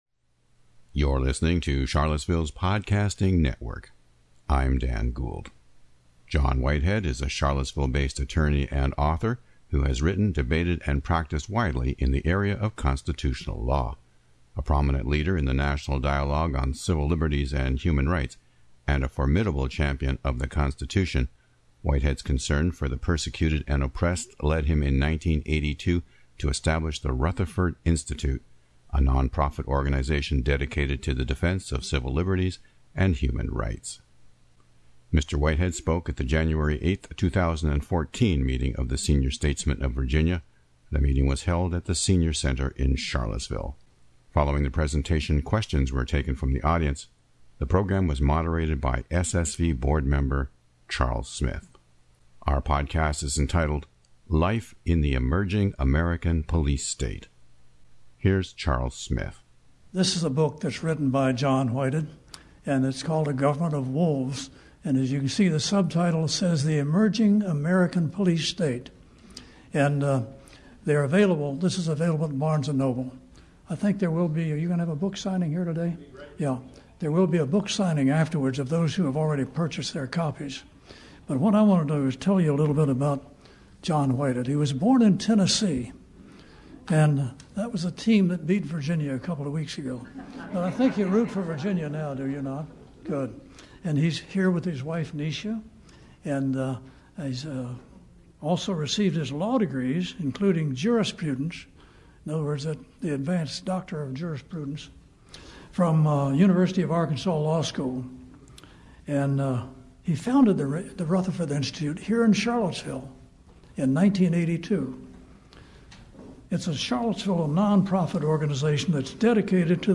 The meeting was held at the Senior Center in Charlottesville. Following the presentation, questions were taken from the audience.